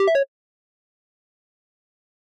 フリー効果音：システム15
微妙な選択肢を選んじゃった時っぽい音です！